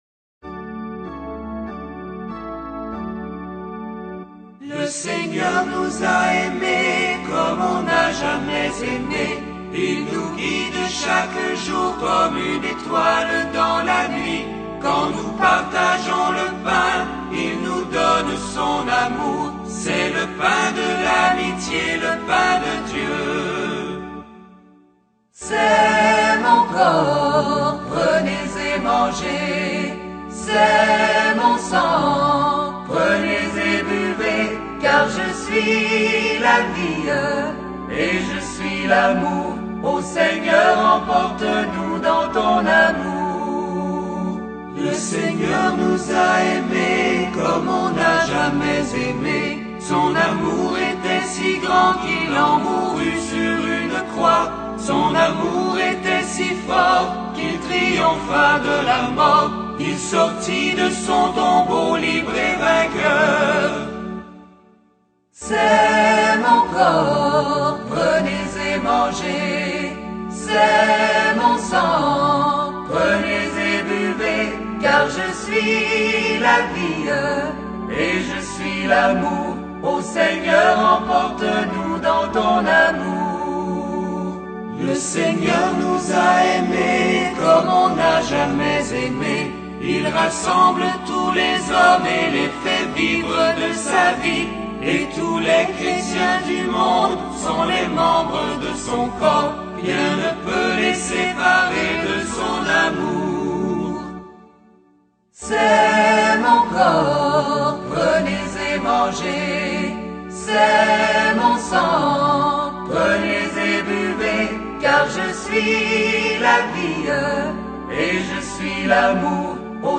2020 CHANTS D'ÉGLISE audio closed https